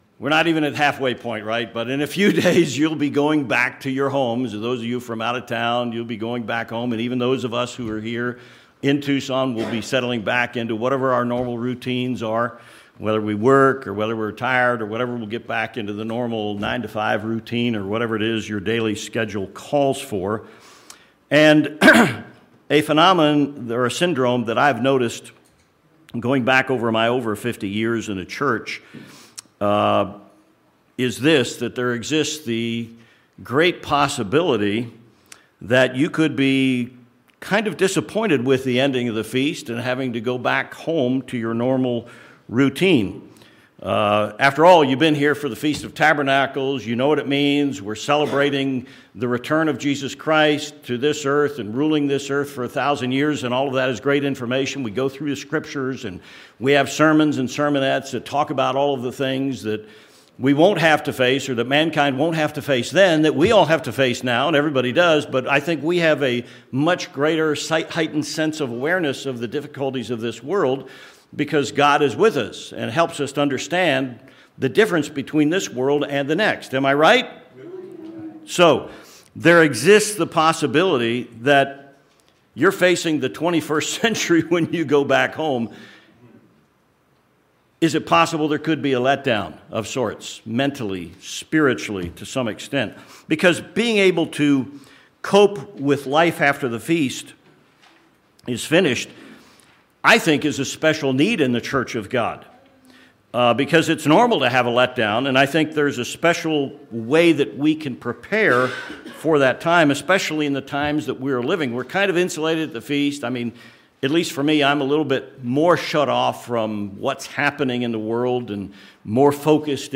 How can you maintain your positive attitude when you return home? This sermon offers 3 tips for our return home.